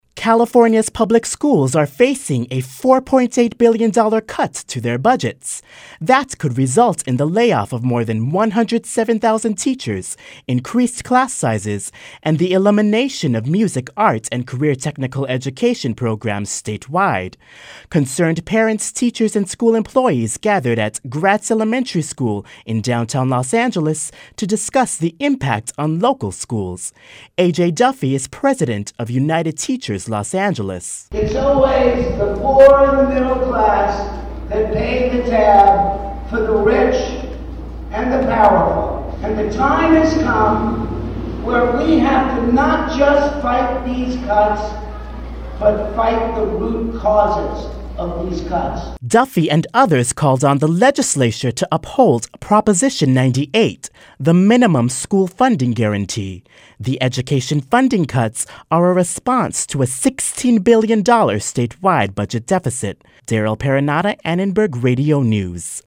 Parents and educators gathered at a press conference to express concern over the Governor's proposed $4.8 billion budget cuts to education funding. LAUSD Superintendent David L. Brewer III was on hand to discuss the impact the cuts will have on local schools. Los Angeles County schools will have to face more than $1.3 billion in projected cuts.